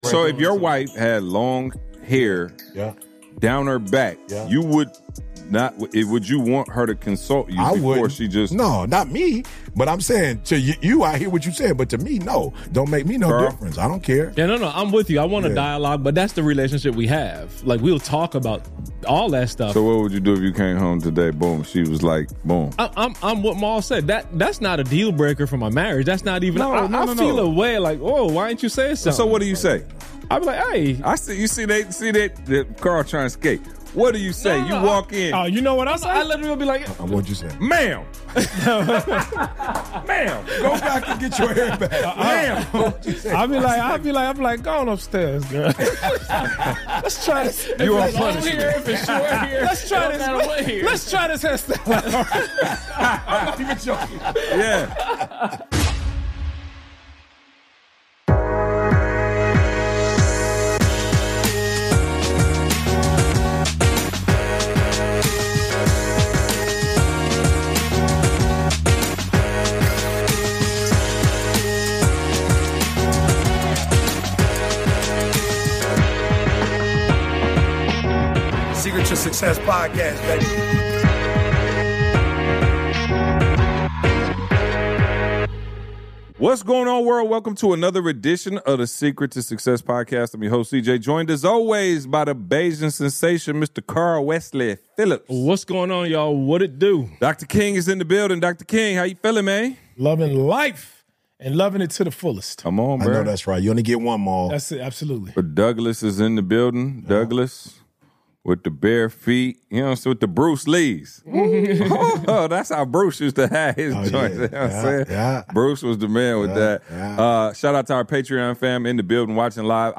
We called THE WIVES in live on the podcast.